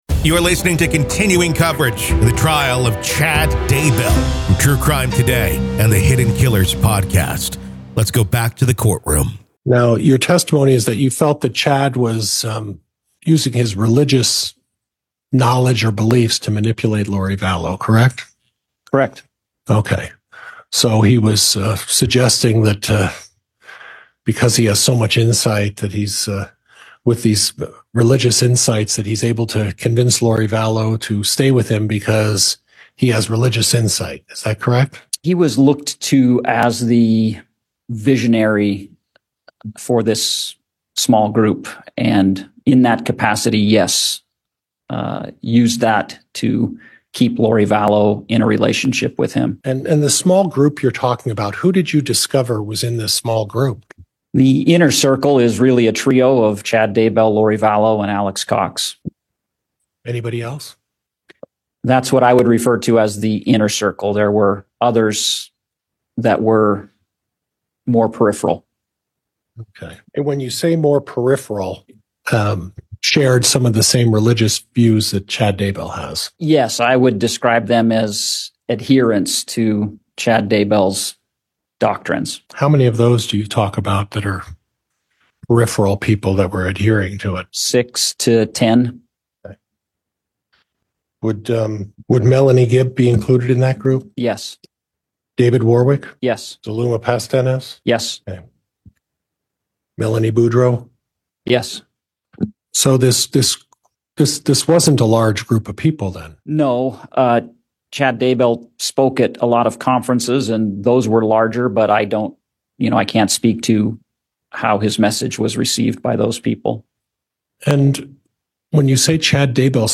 The Trial Of Lori Vallow Daybell | Full Courtroom Coverage / The Trial of Chad Daybell Day 8 Part 2